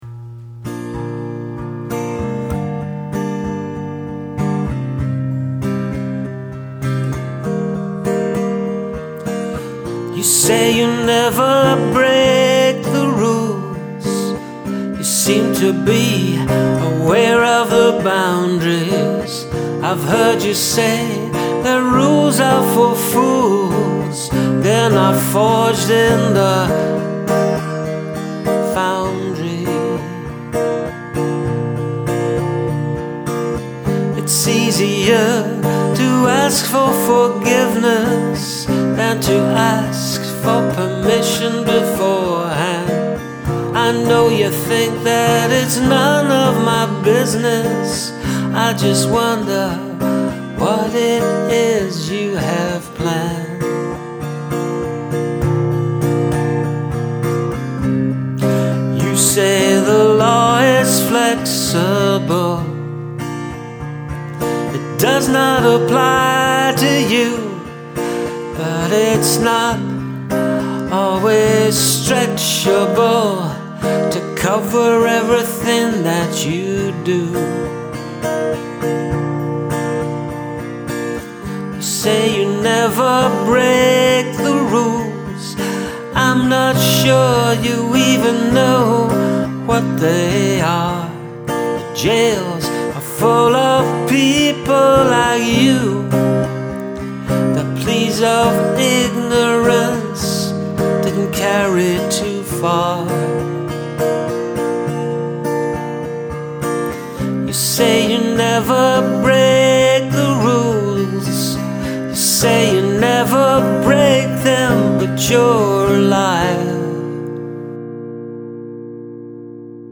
Love the melody, guitar and vocal performance of this.